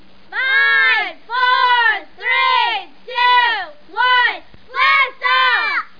BLASTOFF.mp3